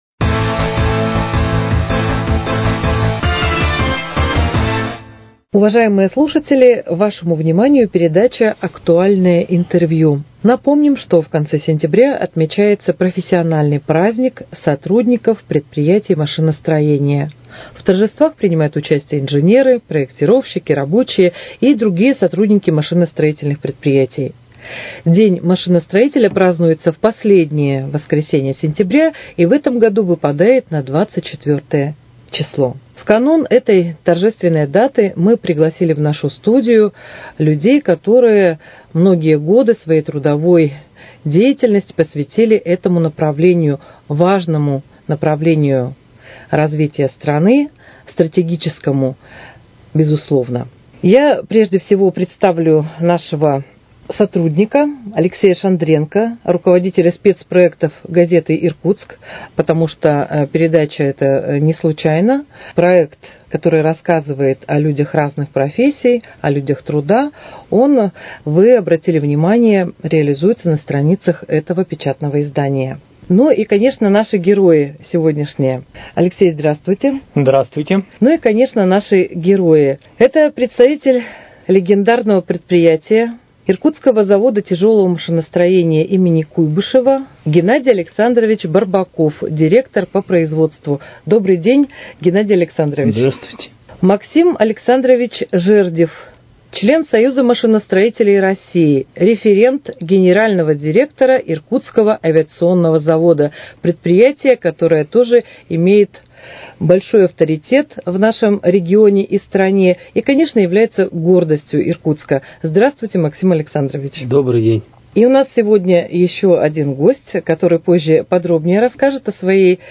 Актуальное интервью: Передача ко Дню машиностроителя